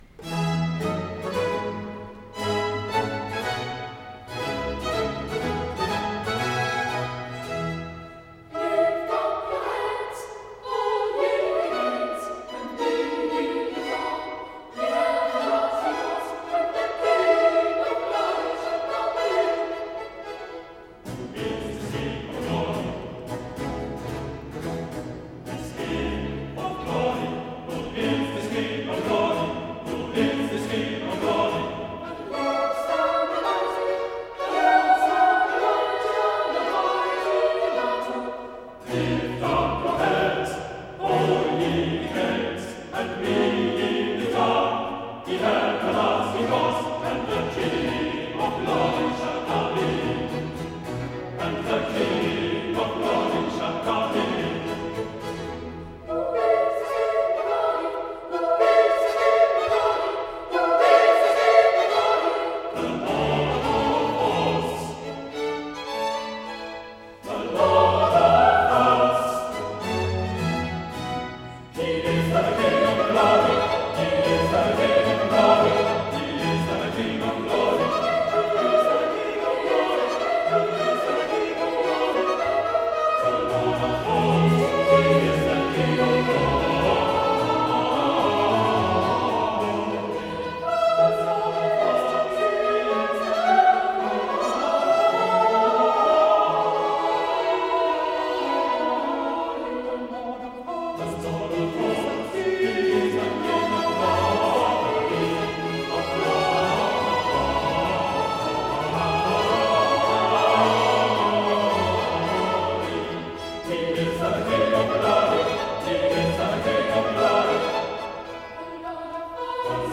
Chorus